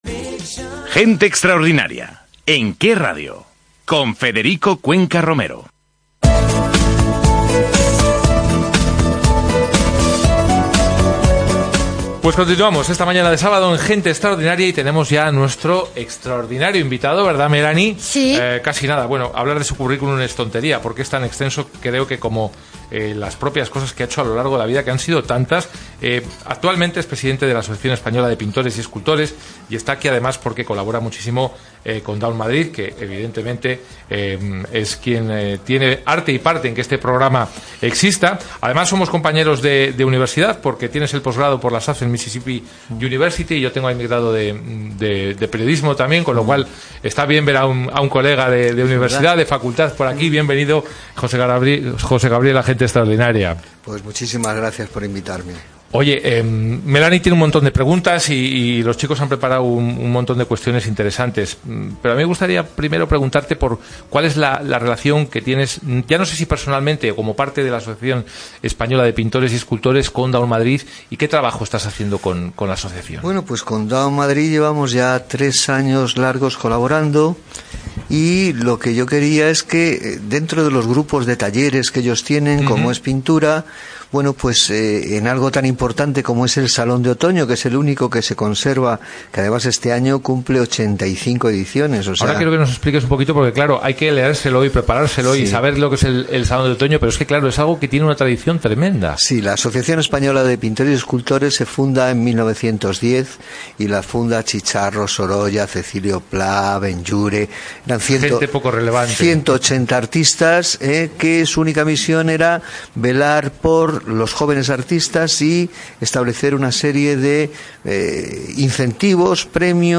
¡Convertimos las entrevistas del programa de Radio Gente Extraordinaria en podcast para que los puedas escuchar en cualquier lado!